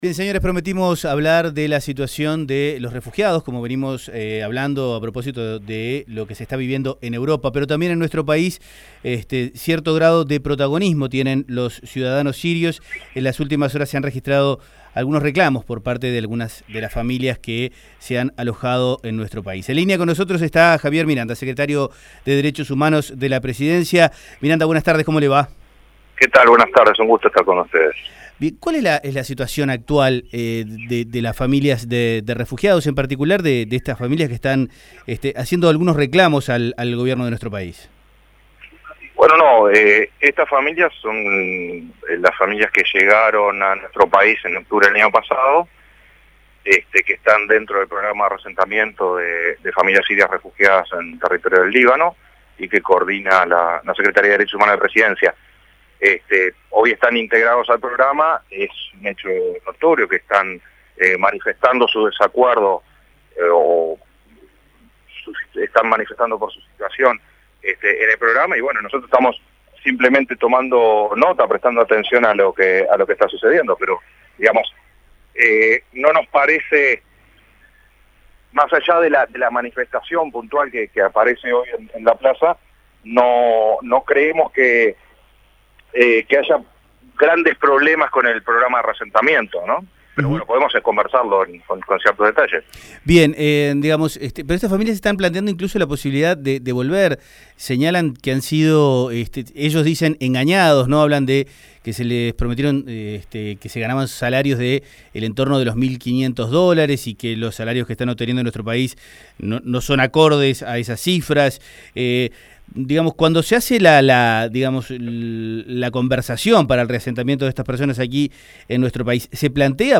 Escuche la entrevista a Miranda